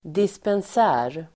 Ladda ner uttalet
Uttal: [dispens'ä:r (el. -angs'ä:r)]